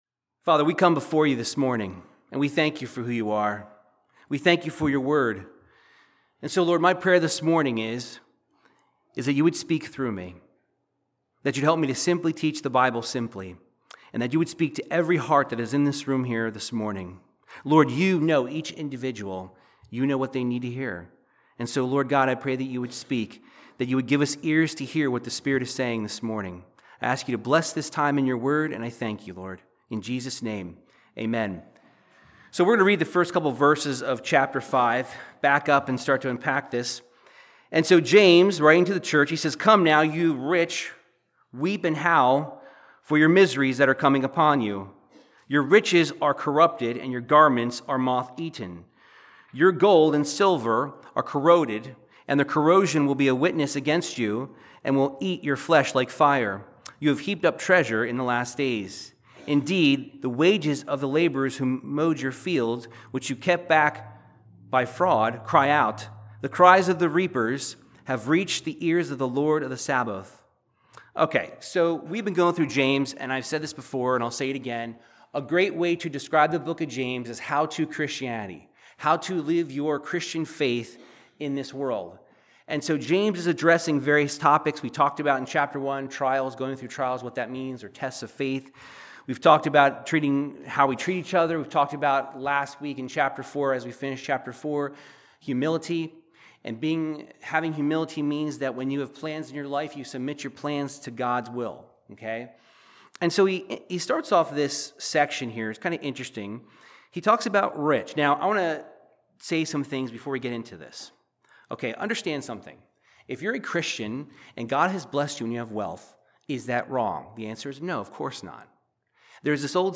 Verse by verse Bible teaching of James 5:1-12 discussing how our chasing God has a more valuable reward than chasing riches and reward now